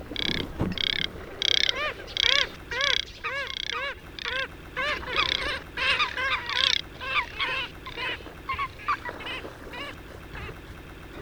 Zijn bijzondere geluid eenmaal een keer gehoord is zeer kenmerkend